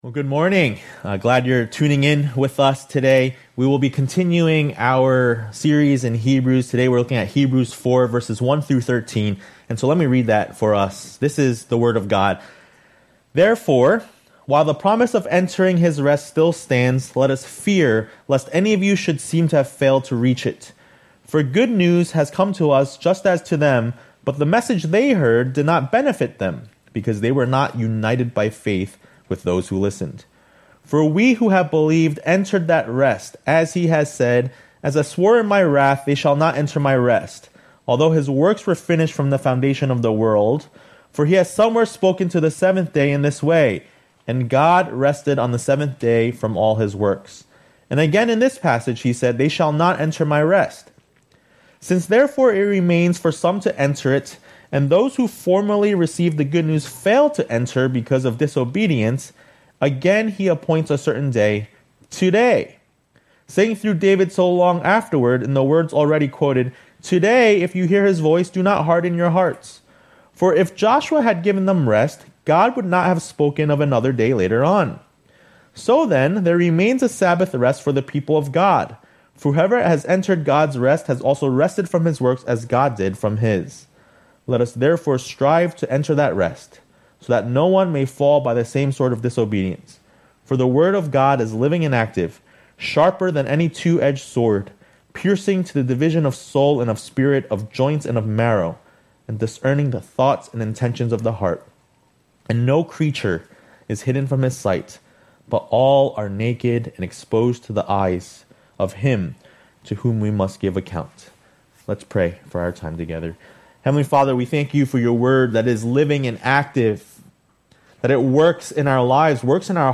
A message from the series "Hebrews."